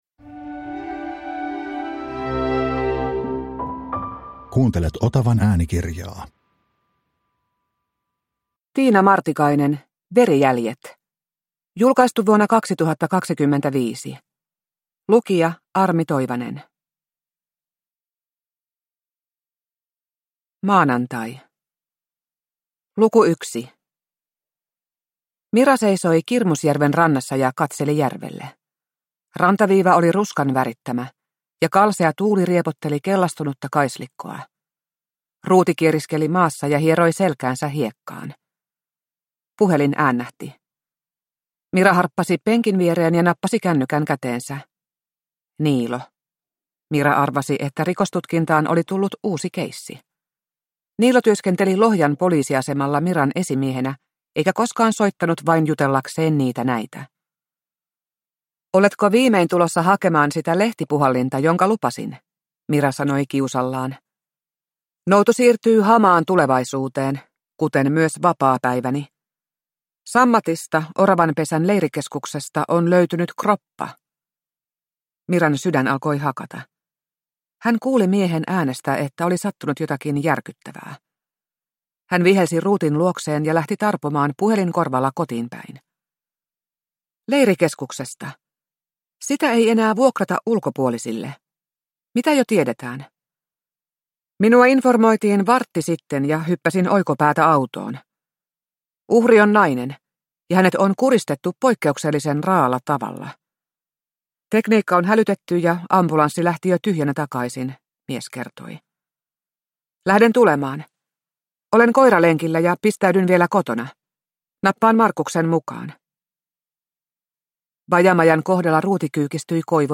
Verijäljet (ljudbok) av Tiina Martikainen